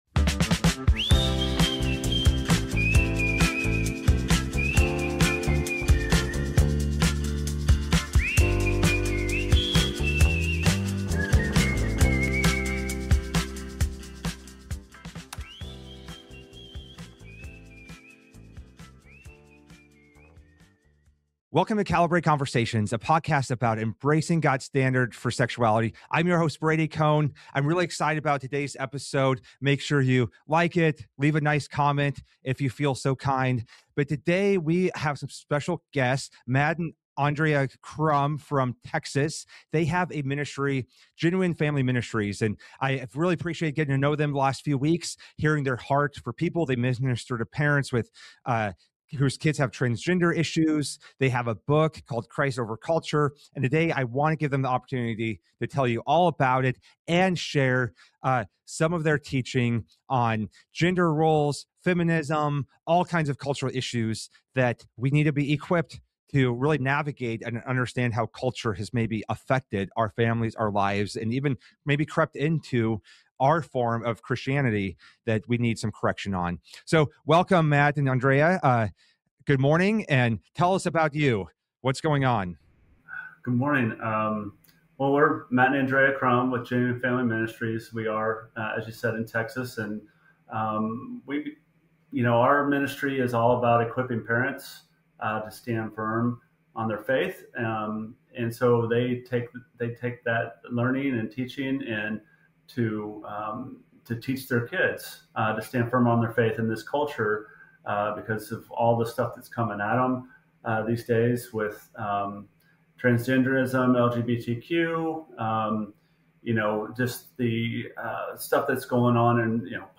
Calibrate Conversations